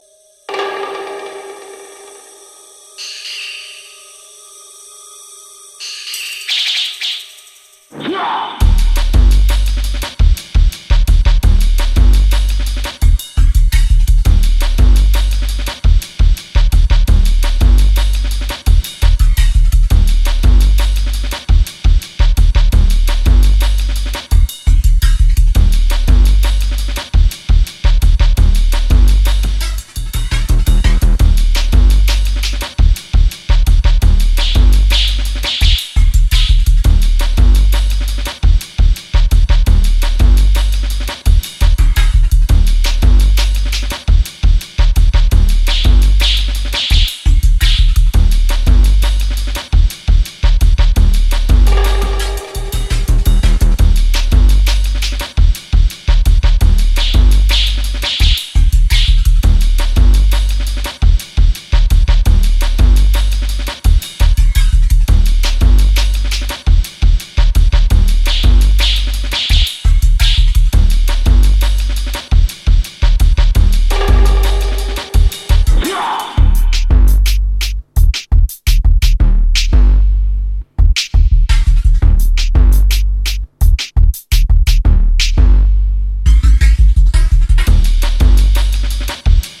[ DRUM'N'BASS / JUNGLE / BASS ]